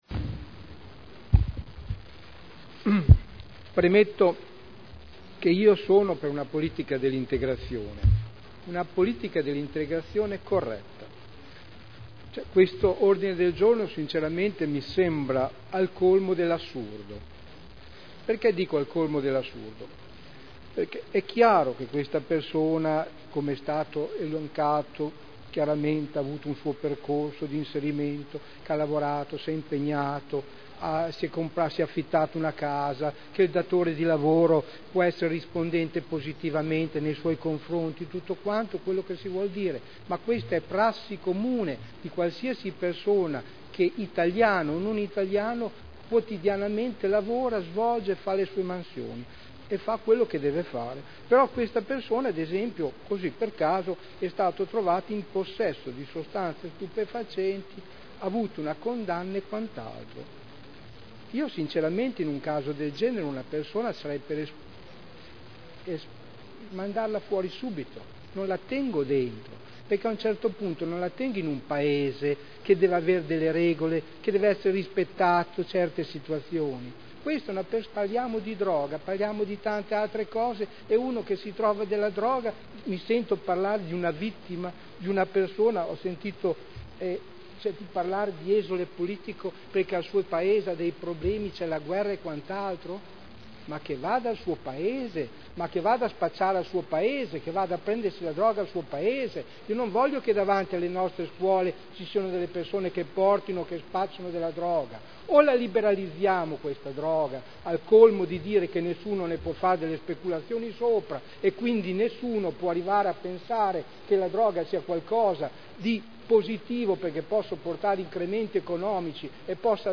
Seduta del 11/11/2010.